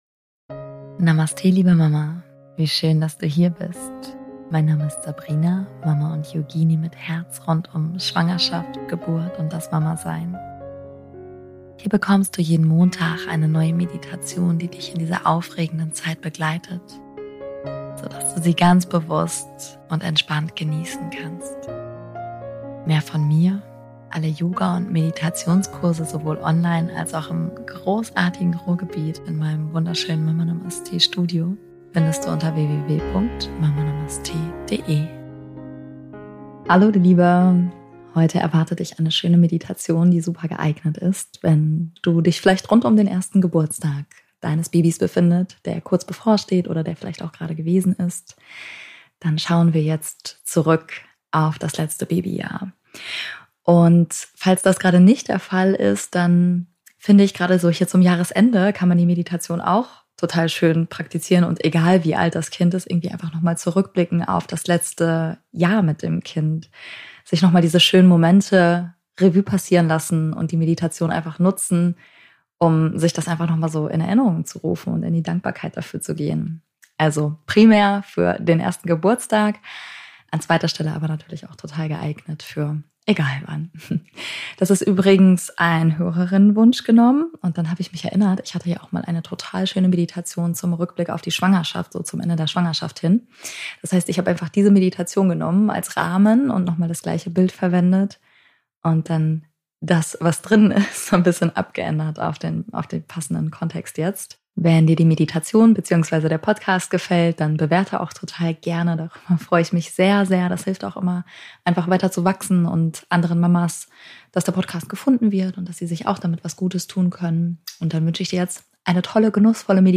In dieser liebevollen Meditation begleite ich dich durch einen achtsamen Rückblick: auf intensive, magische, herausfordernde und wunderschöne Momente.